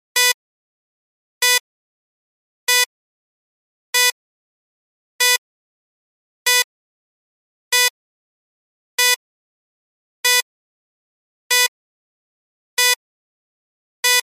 На этой странице представлена подборка звуков BIOS, включая редкие сигналы ошибок и системные оповещения.
Звуковые сигналы ошибок в Биосе: предупреждения, проблемы, уведомления